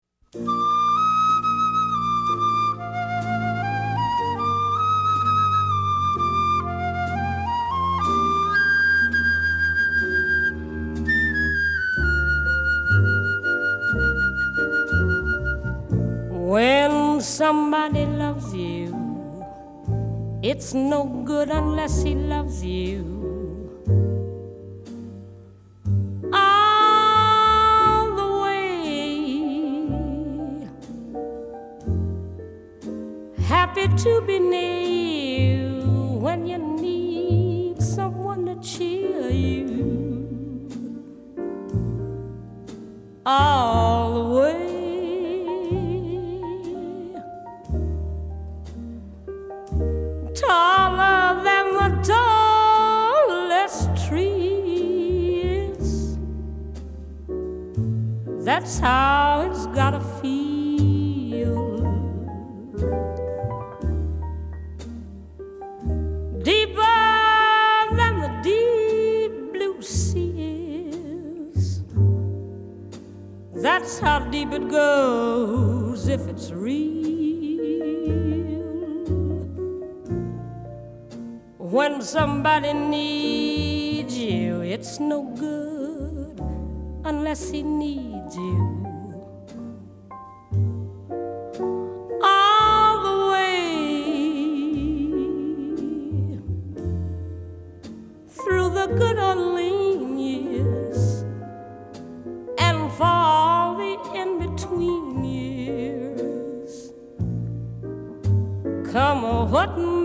爵士及藍調 (575)
☆ 風霜中見清靈怡情的濃郁芳華之聲
Recorded in Englewood Cliffs, NJ; June 21, 1960.